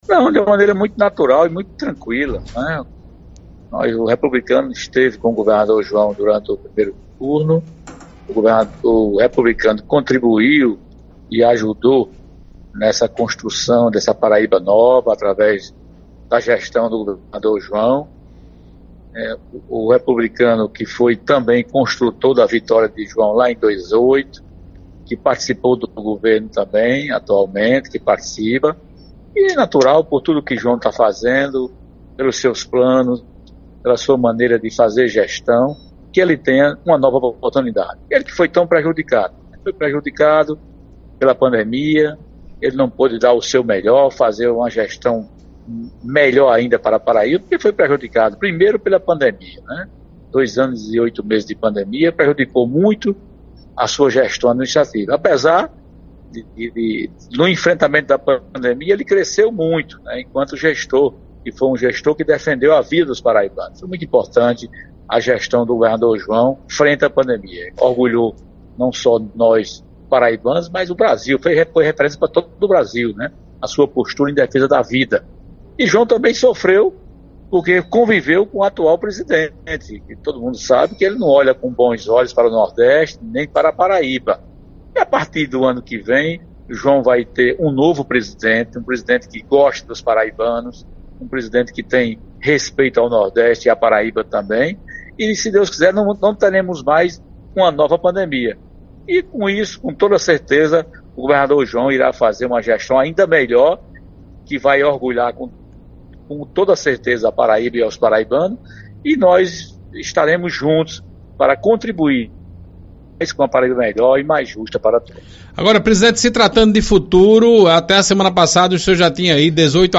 O atual presidente da Assembleia Legislativa da Paraíba, deputado Adriano Galdino (Republicanos), revelou durante entrevista ao programa Arapuan Verdade, do Sistema Arapuan de Comunicação na tarde deste quinta-feira (13), que já conta com a assinatura de 21 parlamentares eleitos e reeleitos que apoiam a sua reeleição a chefe do Poder Legislativo.